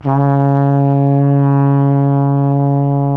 TENORHRN C#1.wav